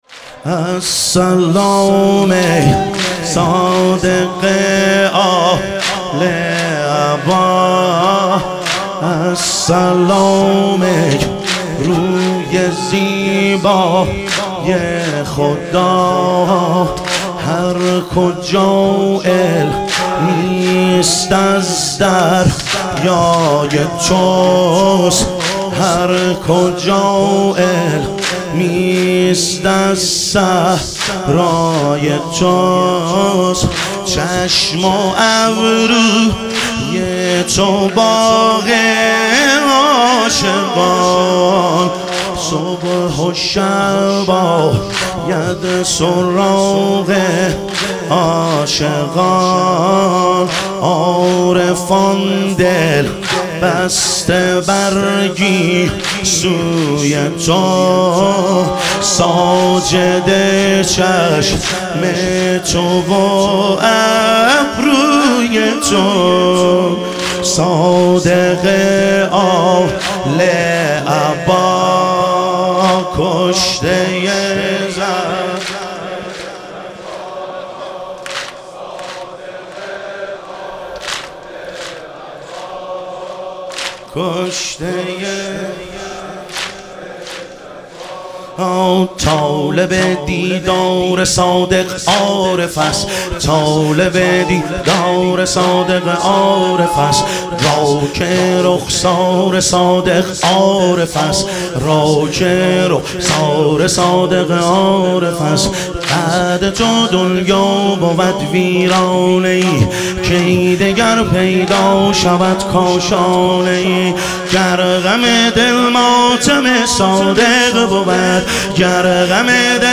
شهادت امام صادق علیه السلام - واحد